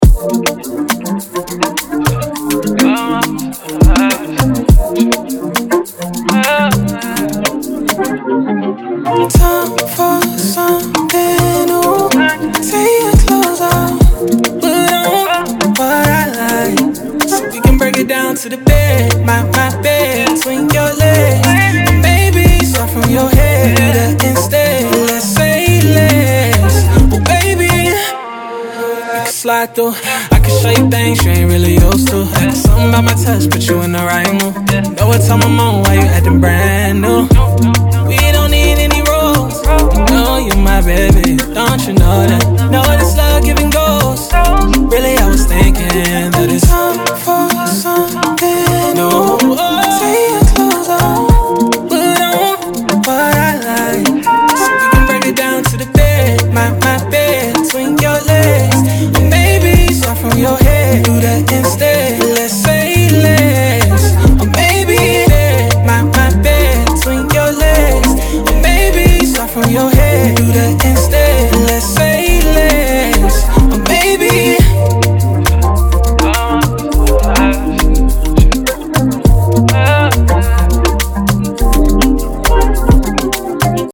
R&B, Afrobeat
Bb Major